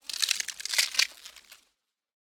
Skeleton Creak Sound Effect MP3 Download Free - Quick Sounds
Skeleton Creak Sound
horror